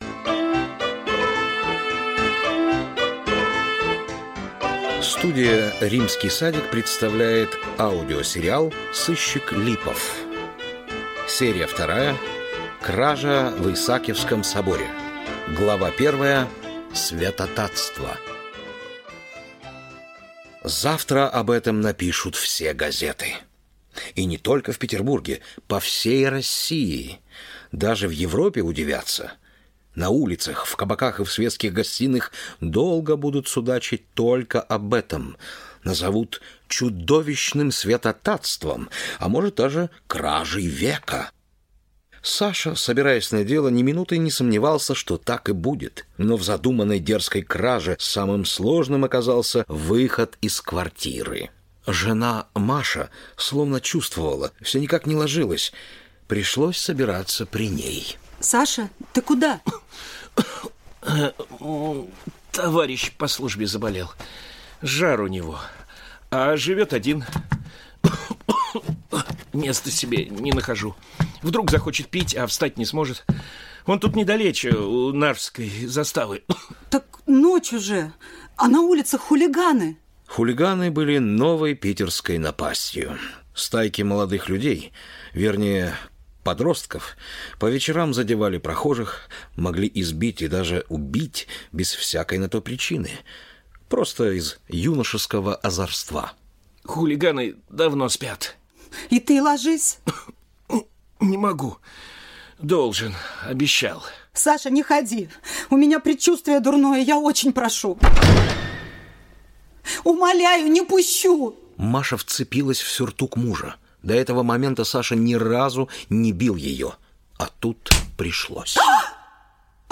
Аудиокнига Сыщик Липов. Серия 2-я. Кража в Исаакиевском соборе | Библиотека аудиокниг